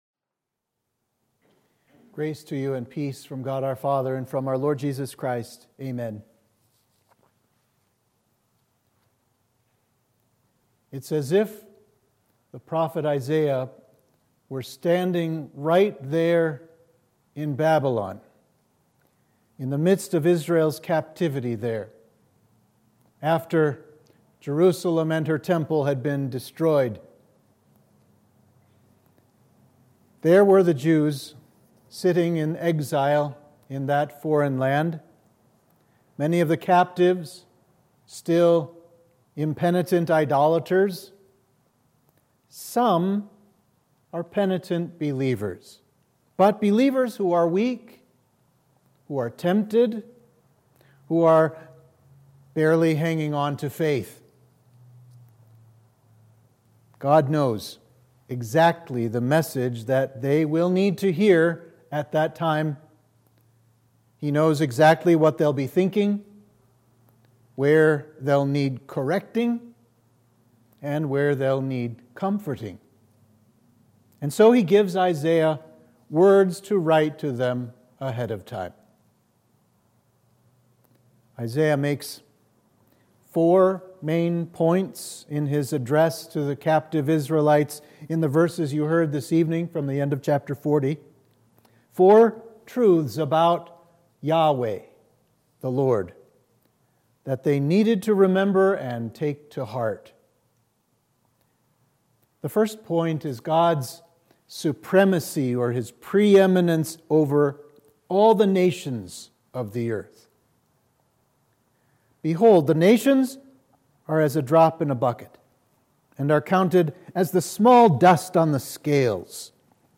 Sermon for Midweek of Advent 2